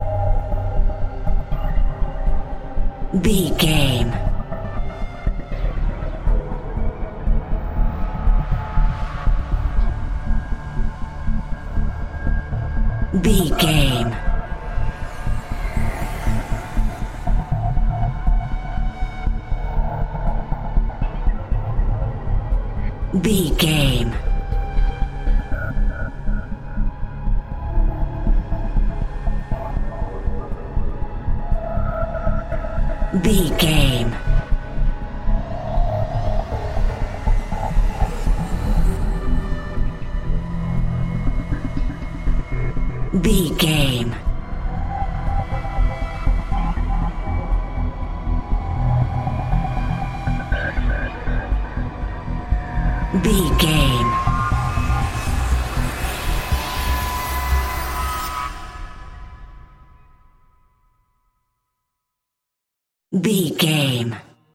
Thriller
Aeolian/Minor
E♭
Slow
synthesiser